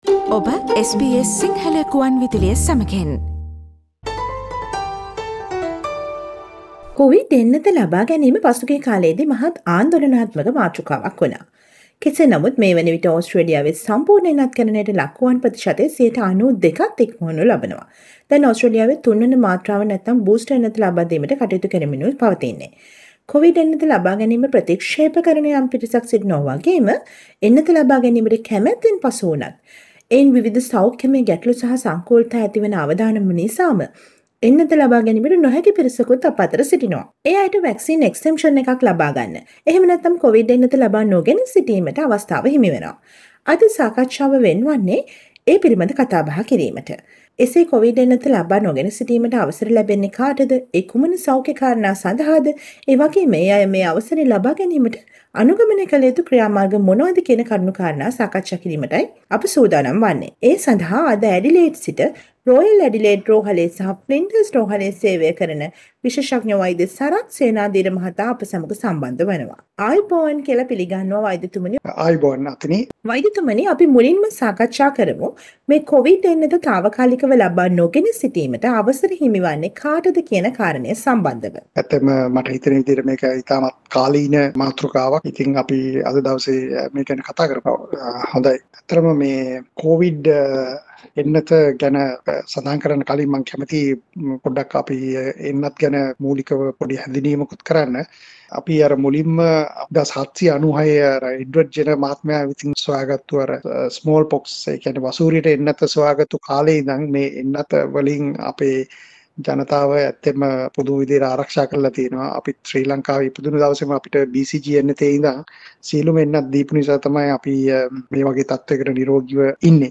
Listen to the SBS Sinhala radio interview on Vaccine exemptions